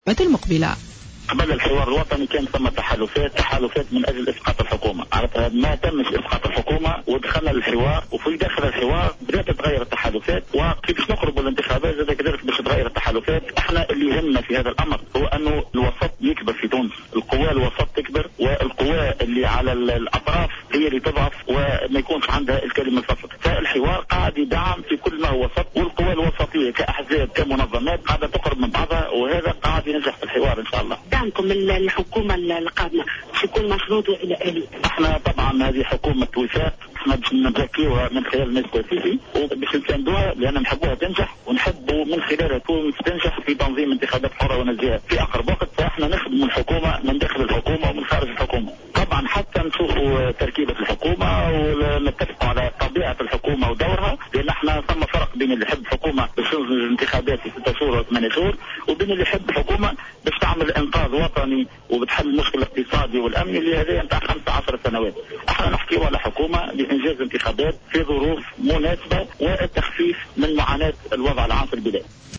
أكد القيادي في حركة النهضة وزير النقل عبد الكريم الهاروني في تصريح لمراسلة جوهرة اف ام أن التحالفات بين الأحزاب تغيرت بعد الحوار الوطني مؤكدا ان كل التحالفات كانت قبل الحوار متجهة نحو المطالبة بإسقاط الحكومة وتغيرت داخل الحوار ومن المنتظر ان تتغير مع قرب موعد الانتخابات على حد قوله.\